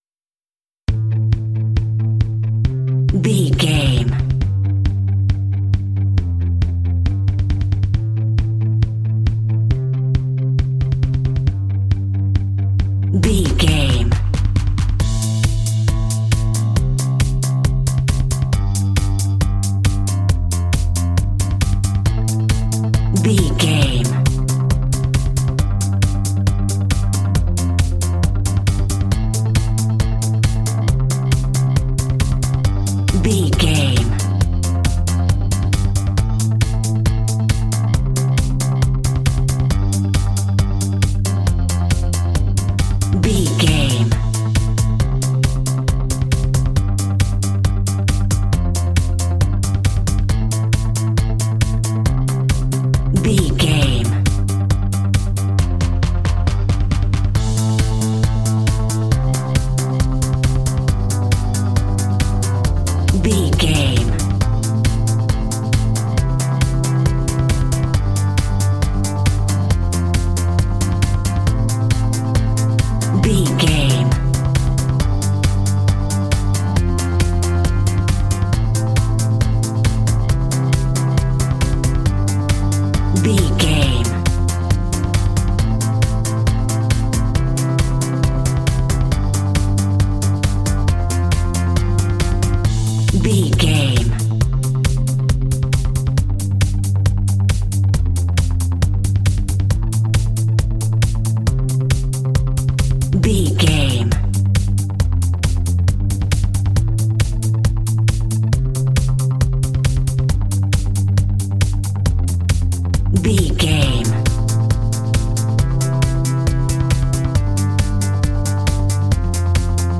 Fast paced
Aeolian/Minor
driving
energetic
bass guitar
drums
synthesiser
synth pop
alternative rock